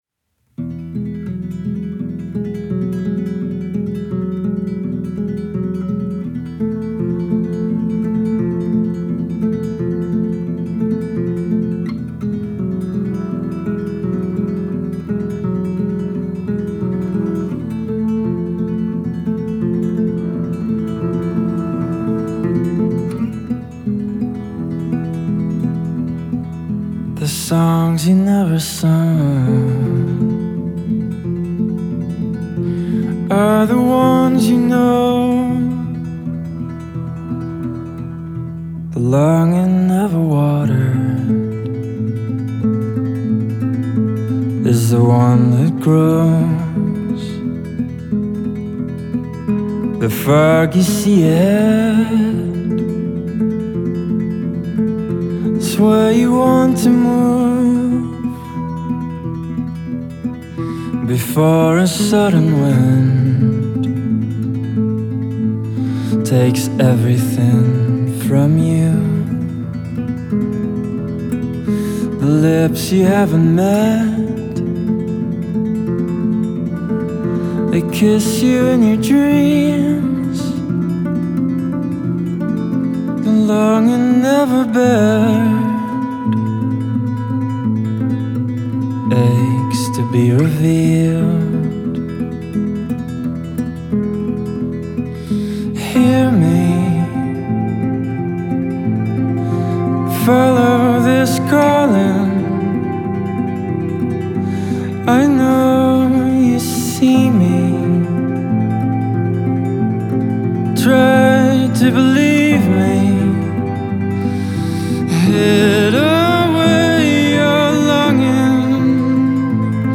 genre: rock,pop rock